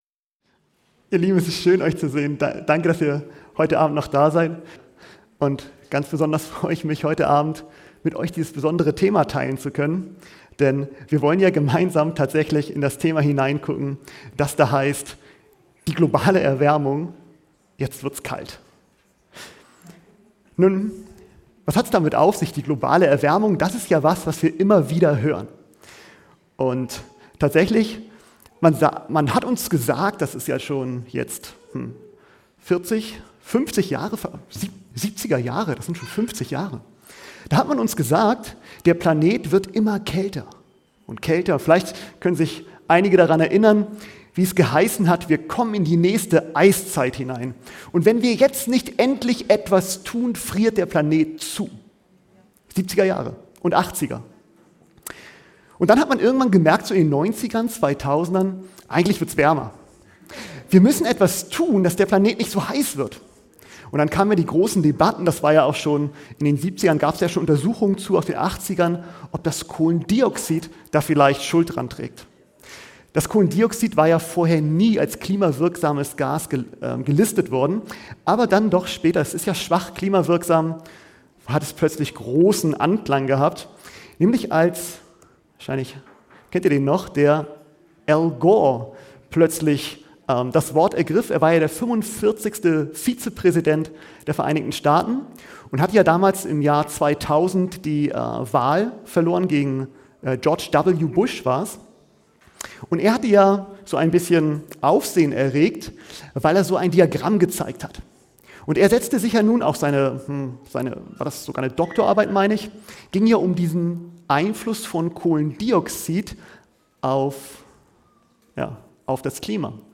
Ein fesselnder Vortrag über Klima, Wahrheit und Prophetie entführt in die Debatten um die globale Erwärmung und die Manipulation von Wahrheiten. Der Redner beleuchtet historische Thesen, religiöse Einflüsse sowie die Rolle des Papsttums in der gegenwärtigen Krise.